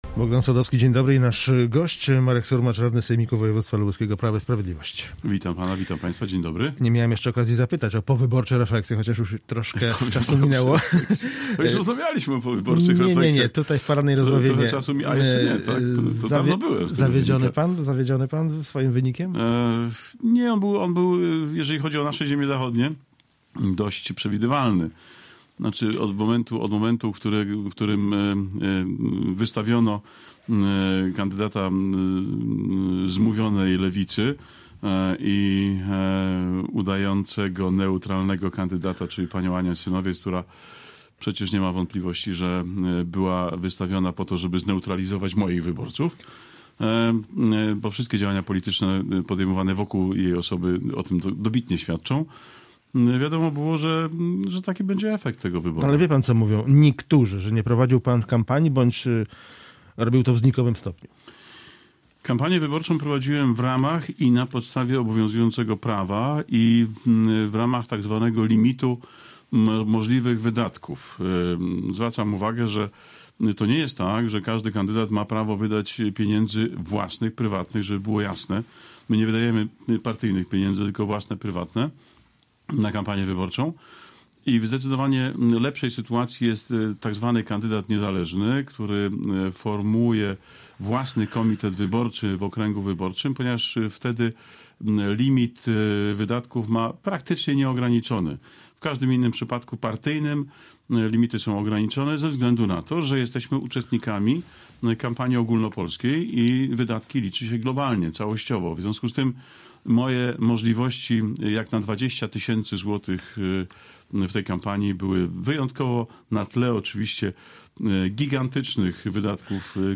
Z radnym wojewódzkim Prawa i Sprawiedliwości rozmawia